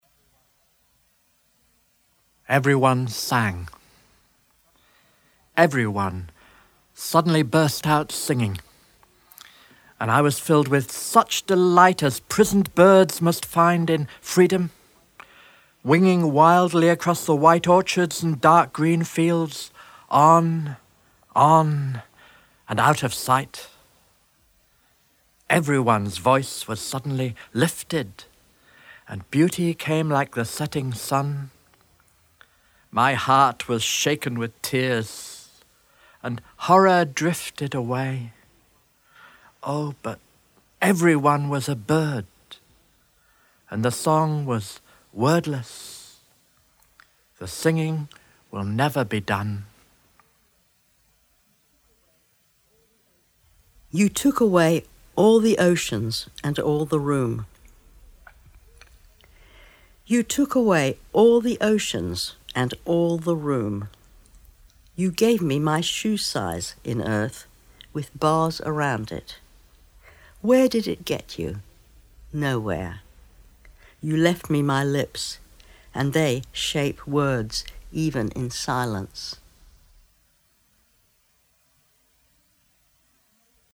The Poet Speaks from Poems on the Underground Audiobook 1994
Everyone Sang by Siegfried Sassoon read by Adrian Mitchell